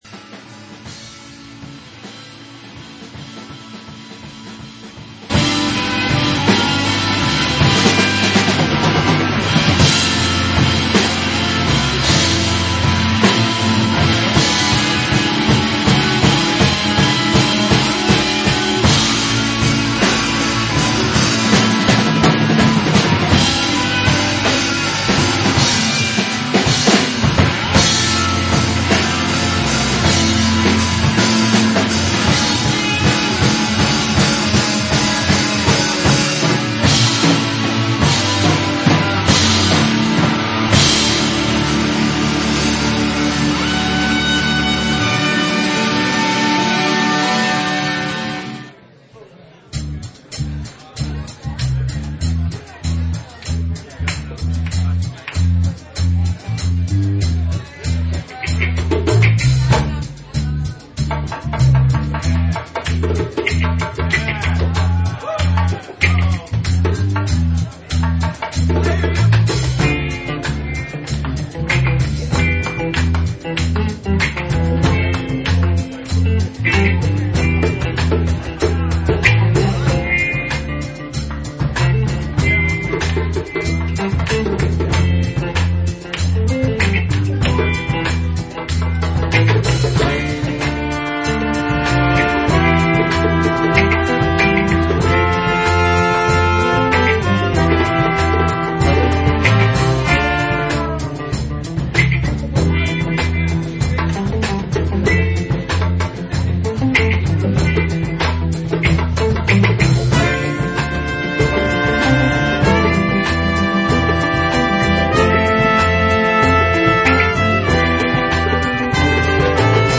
live from Zzebulon